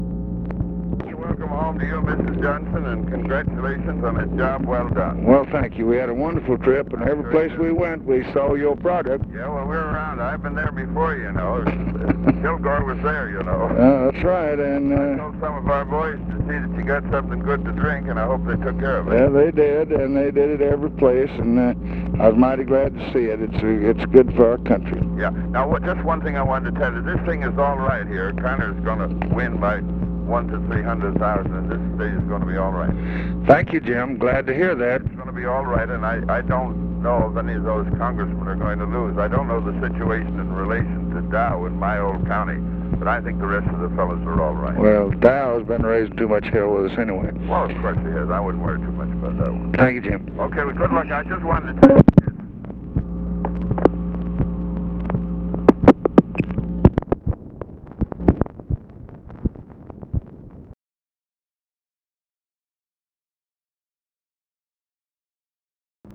Conversation with JAMES FARLEY, November 3, 1966
Secret White House Tapes